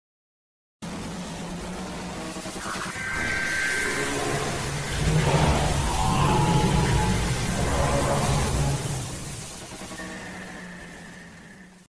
Жуткий звук Сиреноголового SCP 079